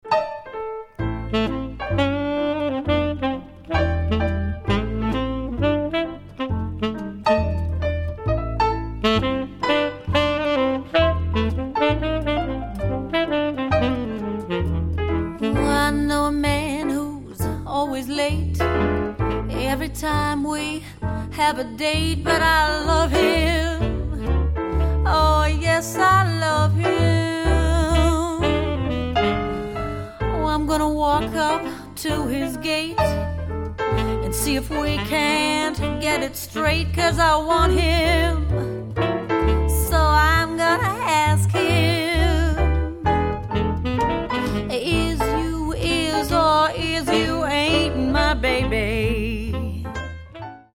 A night of jazz to remember.